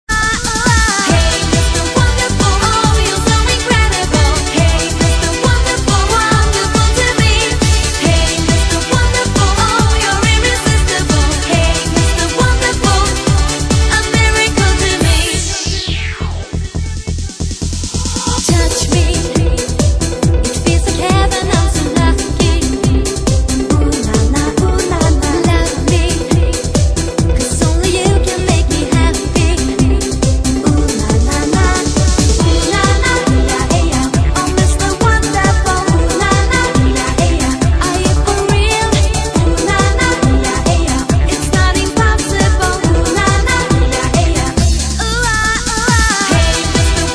酒吧抒情男唱